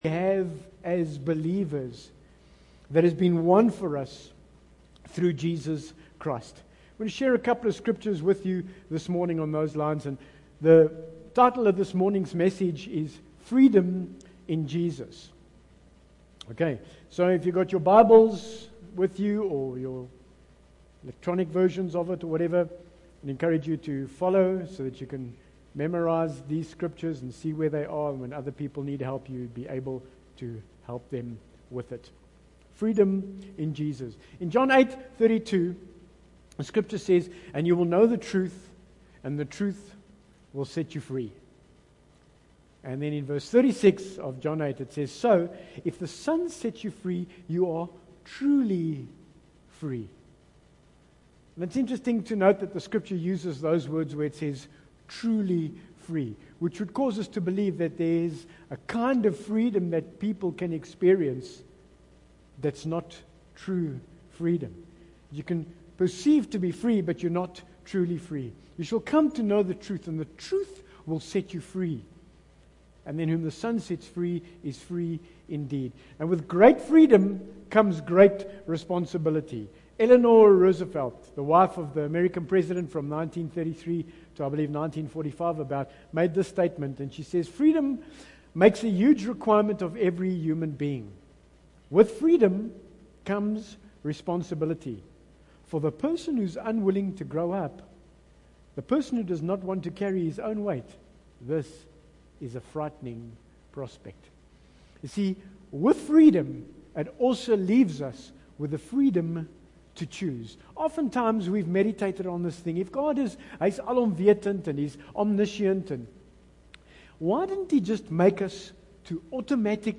Bible Text: John 8:32 | Preacher